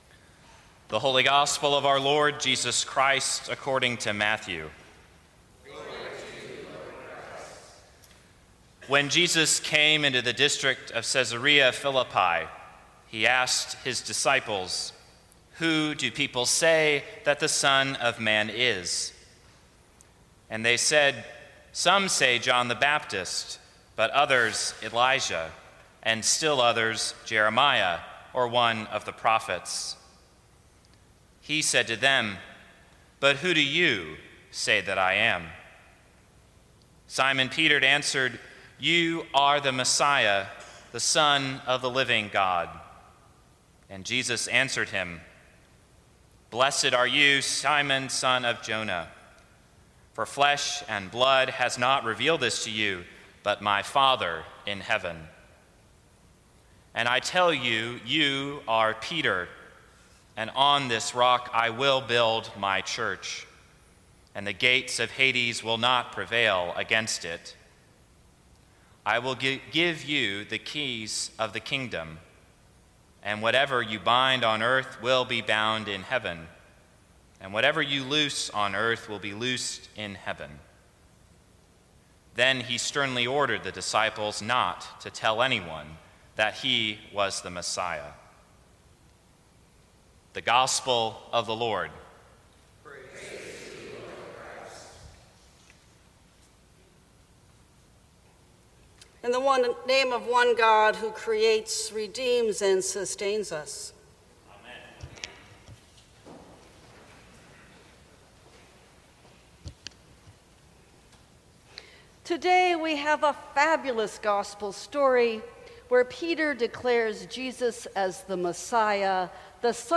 Twelfth Sunday after Pentecost
Sermons from St. Cross Episcopal Church Keys of the Kingdom Sep 01 2017 | 00:13:27 1x 00:00 / 00:13:27 Subscribe Share Apple Podcasts Spotify Overcast RSS Feed Share Link Embed